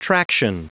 Prononciation du mot traction en anglais (fichier audio)
Prononciation du mot : traction